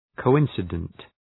Προφορά
{kəʋ’ınsıdənt} (Επίθετο) ● συμπίπτων